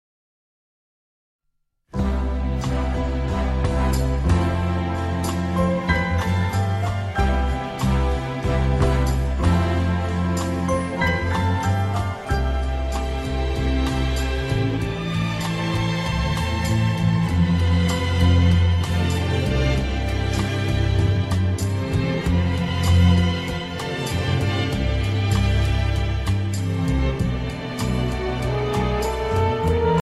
Usazování hostů